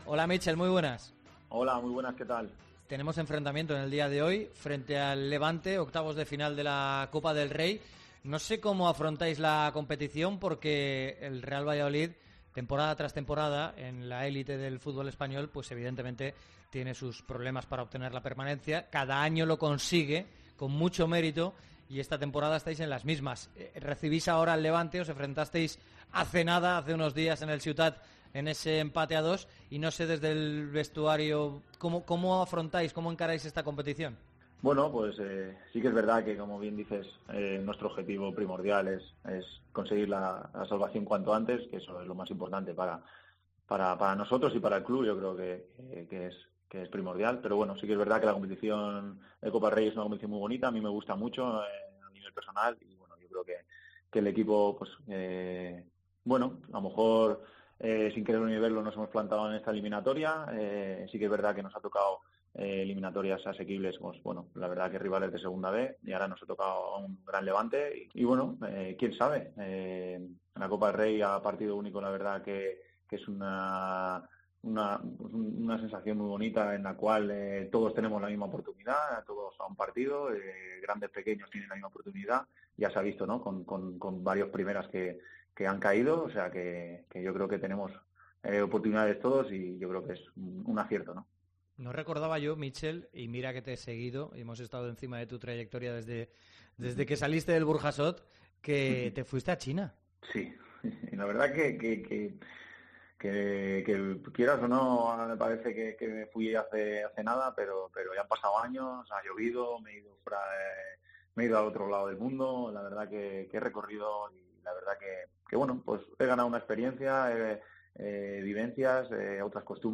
ENTREVISTA COPE
AUDIO. Entrevista a Míchel Herrero en Deportes COPE Valencia